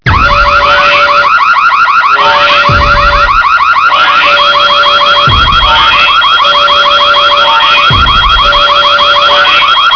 wwe-right-to-censor-alarm_24899.mp3